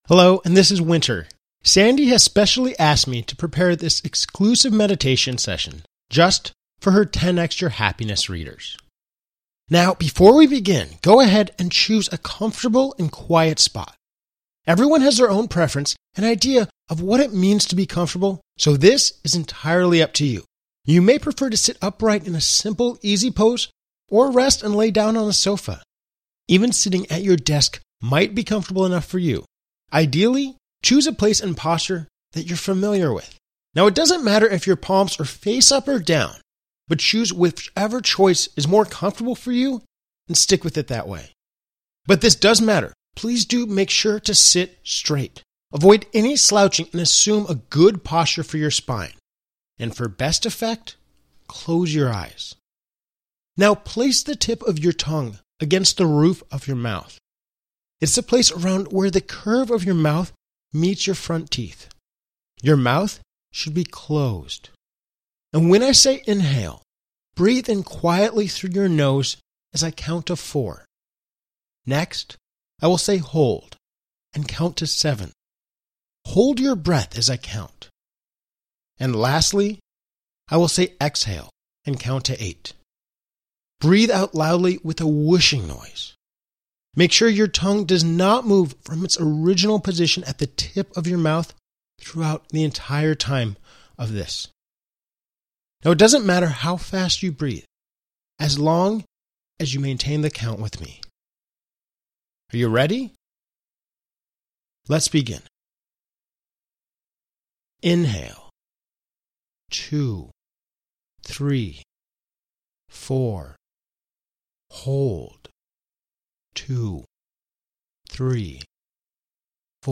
10XYourHappinessMeditation.mp3